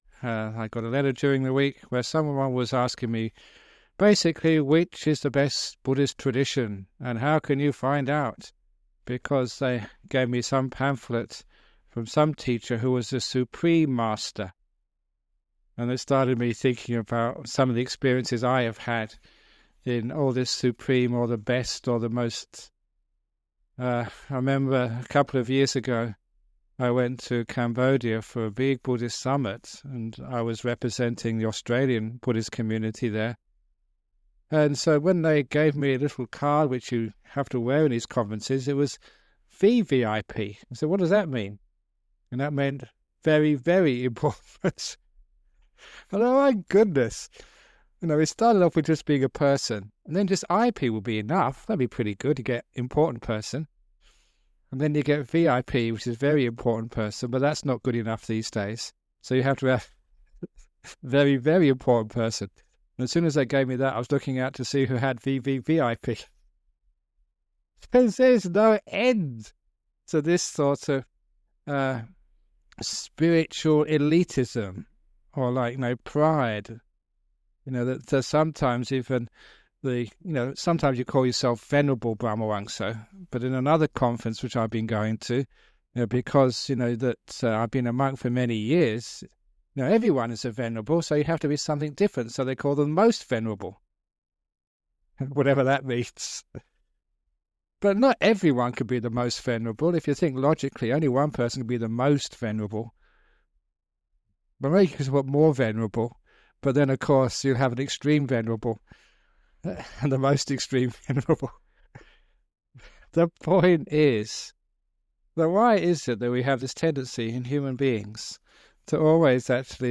Remastered classic teachings of the greatest meditation master in the modern Western world - Ajahn Brahm!